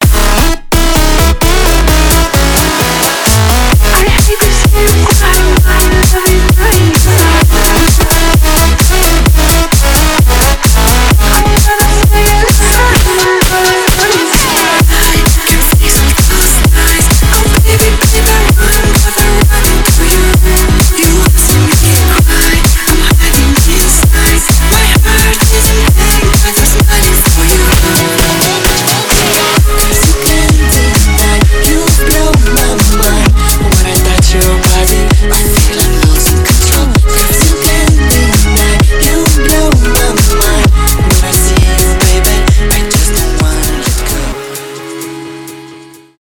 electro house
клубные
edm